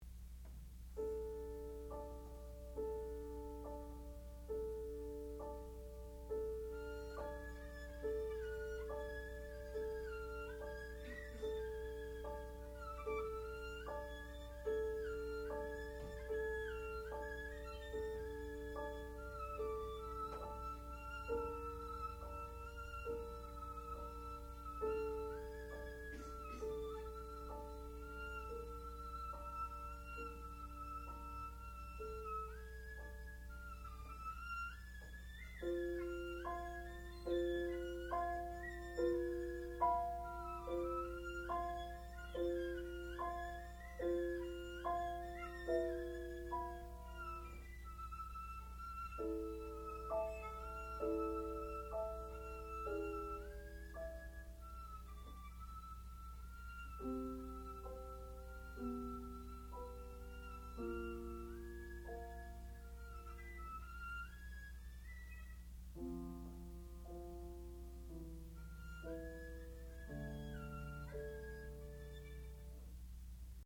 sound recording-musical
classical music
violin
piano
Advanced Recital